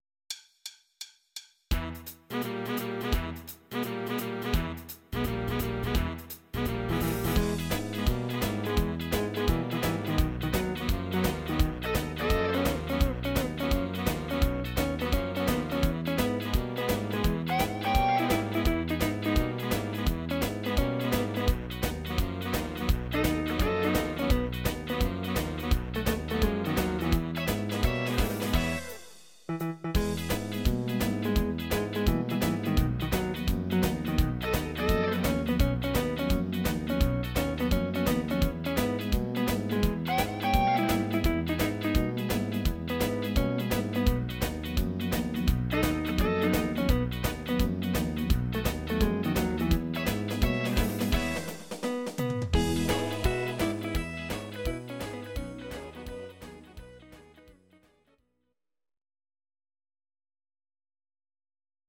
Audio Recordings based on Midi-files
Pop, Dutch, 1980s